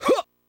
Huhhh.wav